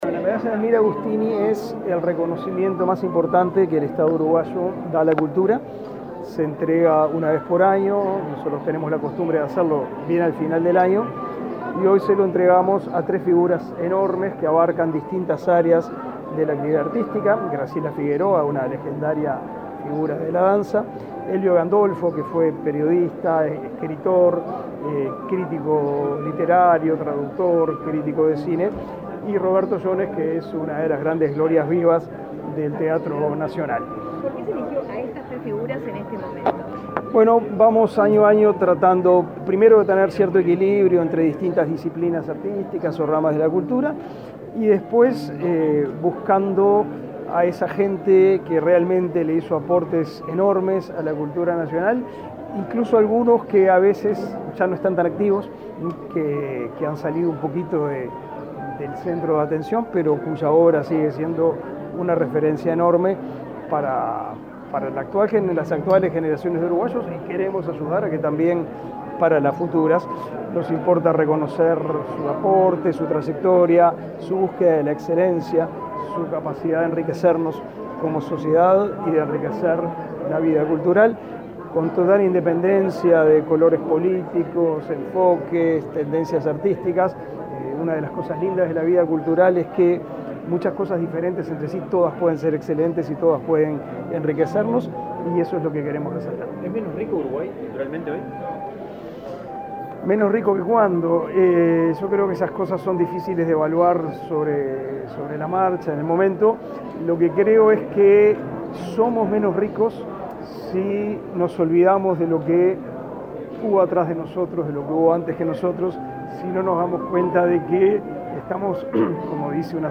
Declaraciones del ministro de Educación y Cultura, Pablo da Silveira
Declaraciones del ministro de Educación y Cultura, Pablo da Silveira 16/12/2022 Compartir Facebook X Copiar enlace WhatsApp LinkedIn La directora nacional de Cultura, Mariana Wanstein, y el ministro Pablo da Silveira, participaron del acto del entrega de las medallas Delmira Agustini, realizado este viernes 16 en el Palacio Taranco de Montevideo. Luego, el secretario de Estado dialogó con la prensa.